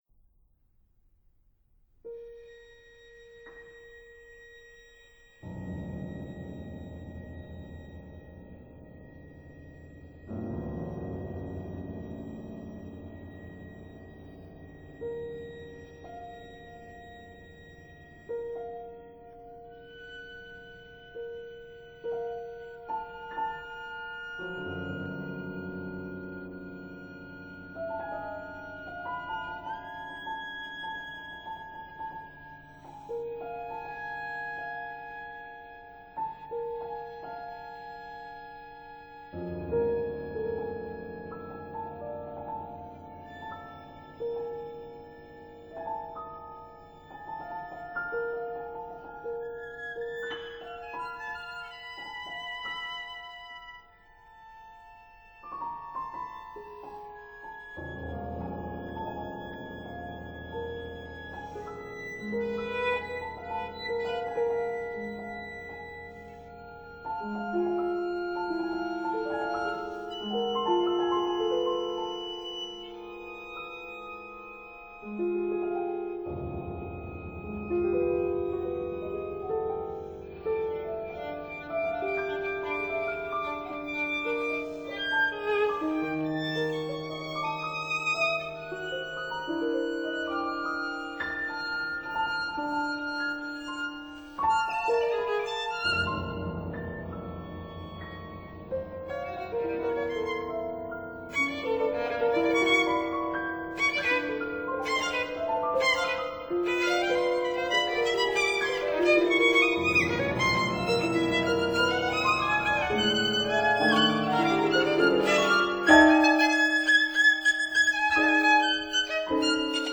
Violin Sonatas